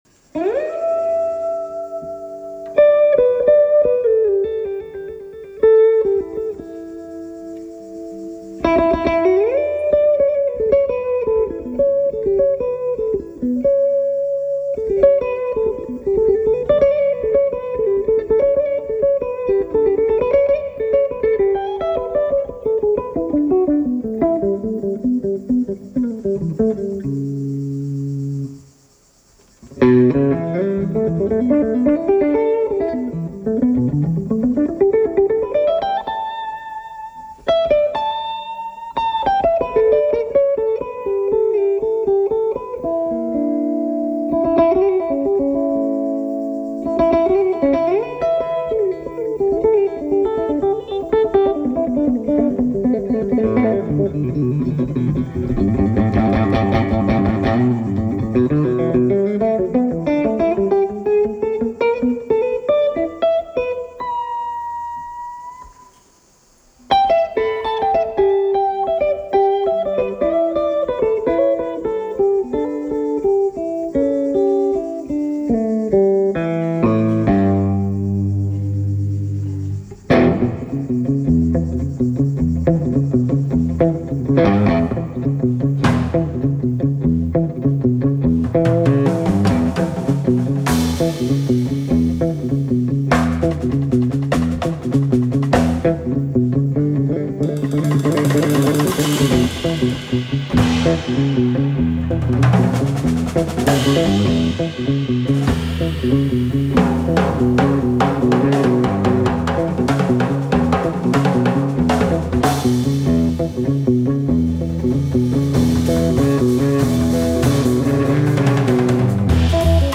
American guitarist
bass
abundant cadence and clarity.